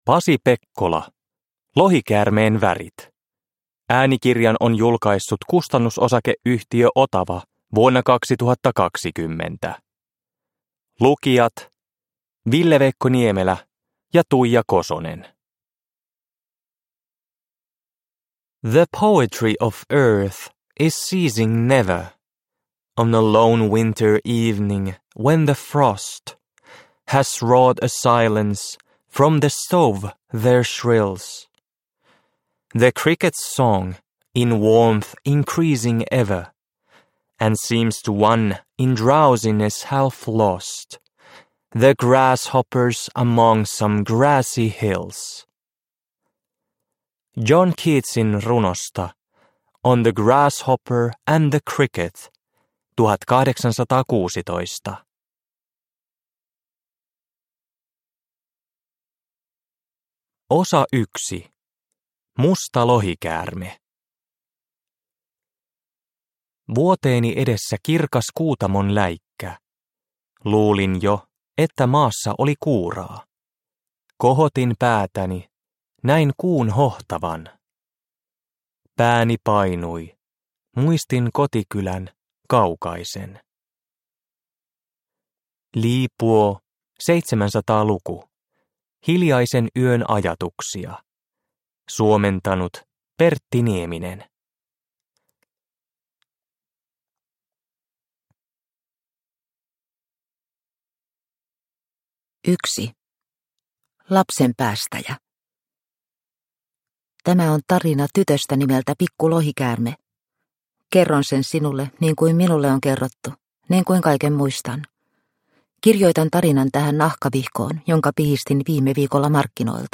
Lohikäärmeen värit – Ljudbok – Laddas ner